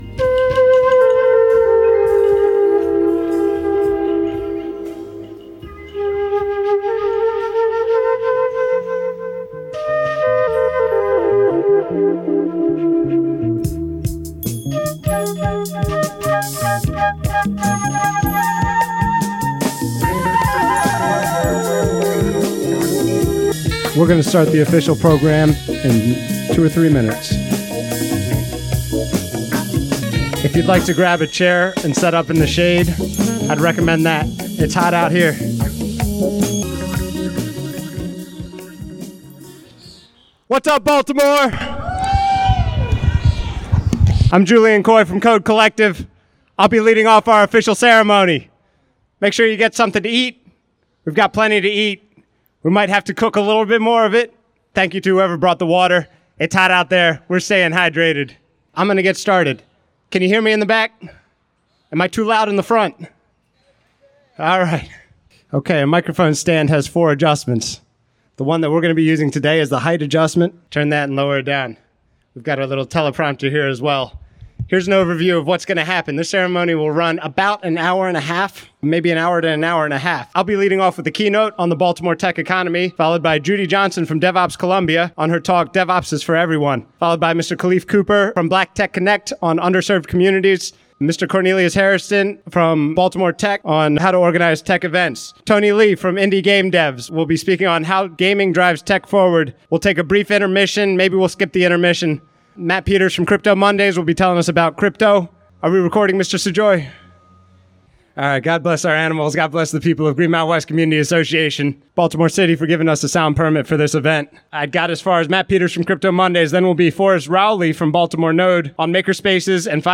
Tech Unity was a first-of-its-kind cookout designed to unite the forces of the Baltimore Metropolitan Tech Economy on June 28th, 2025.